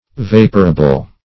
Search Result for " vaporable" : The Collaborative International Dictionary of English v.0.48: Vaporable \Vap"o*ra*ble\, a. Capable of being converted into vapor by the agency of heat; vaporizable.